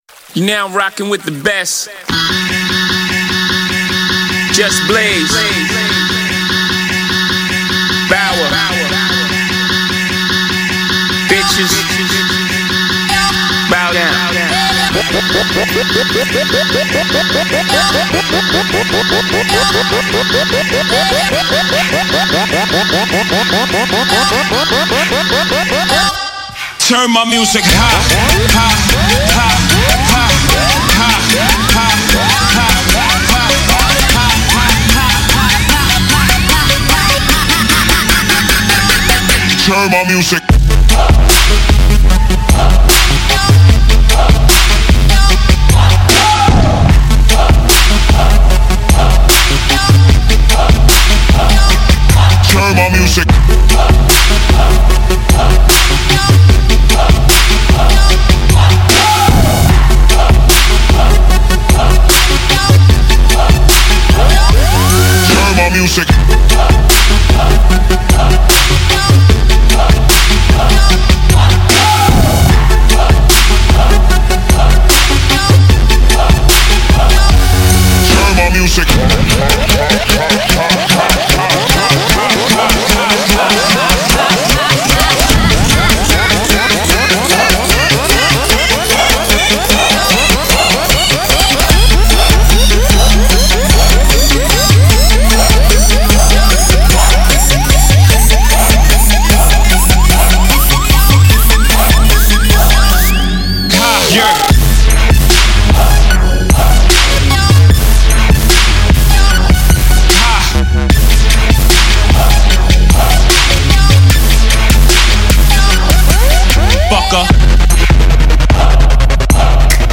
HipHop 2010er